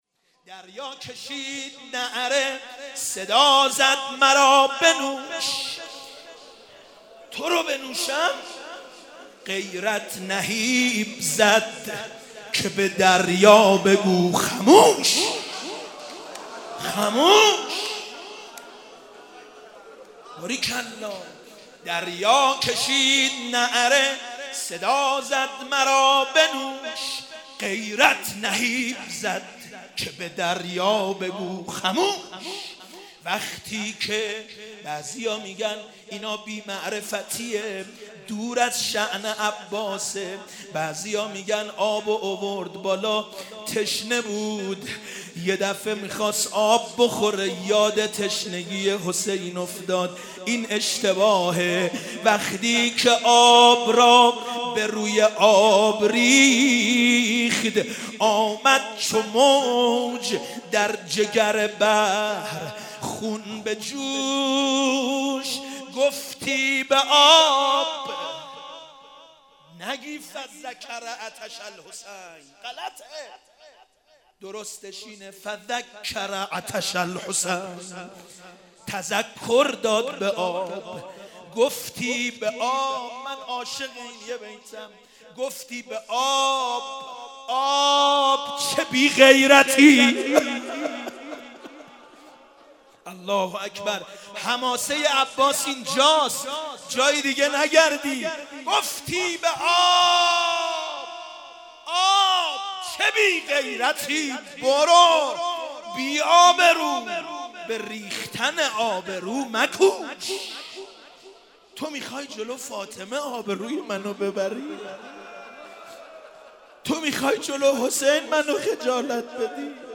در صفت ابالغوث بودن حضرت قمرالعشیره (مقتل خوانی)